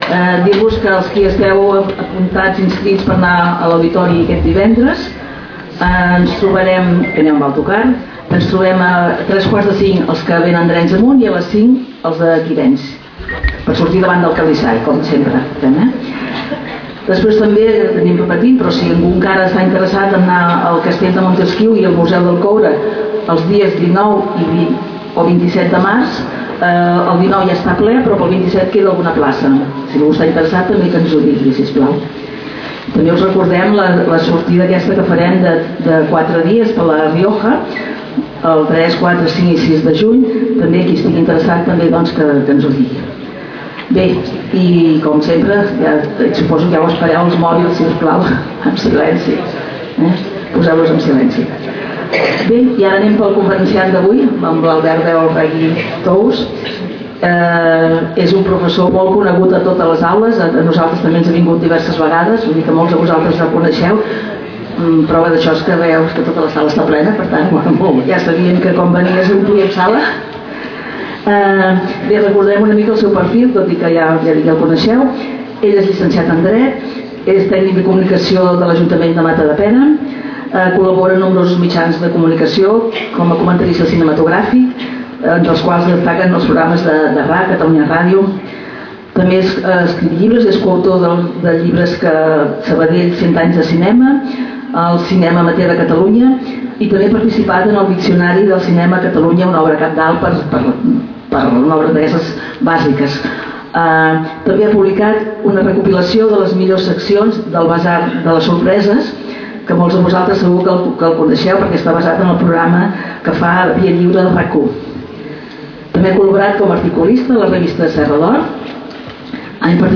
Lloc: Casal de Joventut Seràfica
Aula-03-març-2026.mp3 Categoria: Conferències Notícies El menjar és un dels grans regals de la vida.